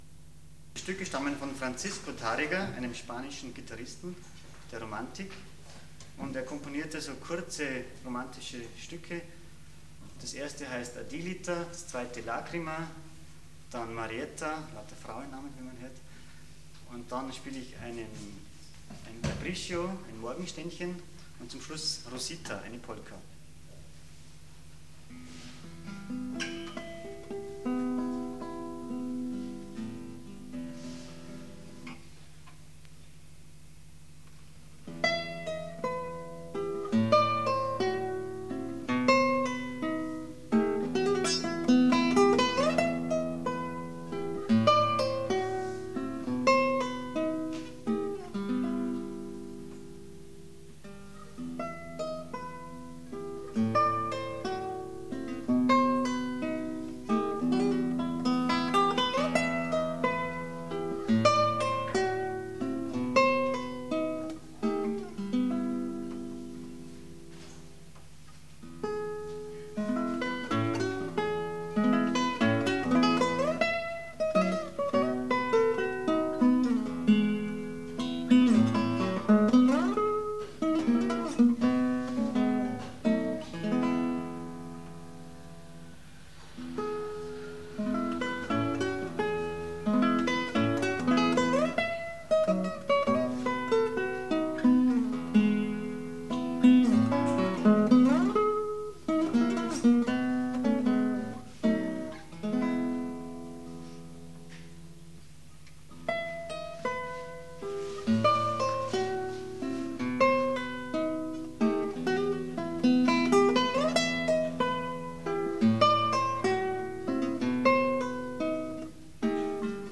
Live (1993)